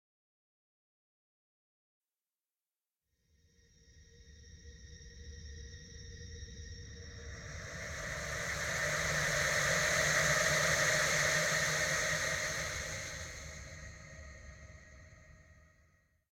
Shudder ghost 2.ogg